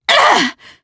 Demia-Vox_Damage_jp_03_b.wav